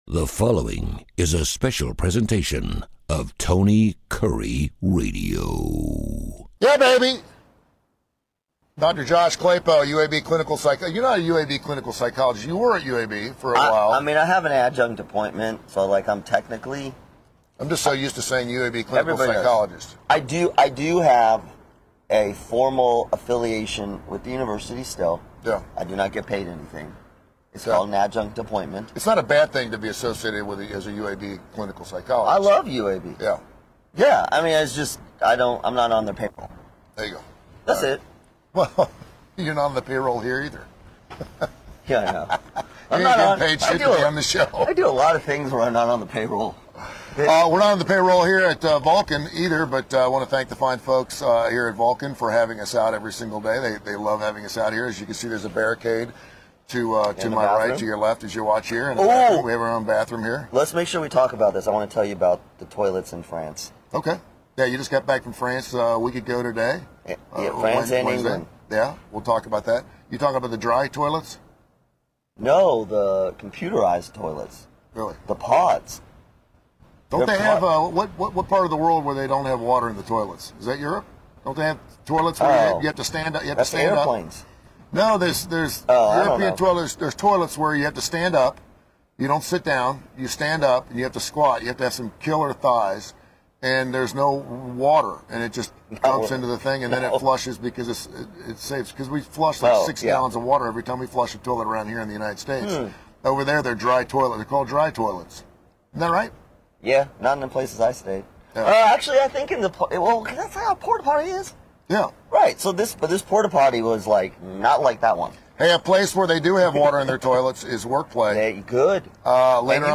The guys are back and live from Vulcan! Can we really see similarities in relationships and our government? Is there a way to compromise on ideological differences and still function as a unit?